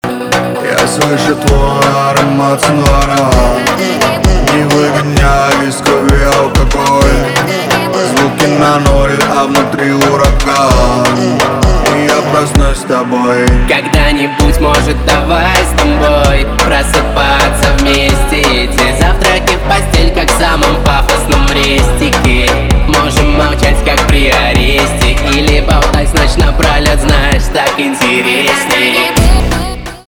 поп
битовые , басы , качающие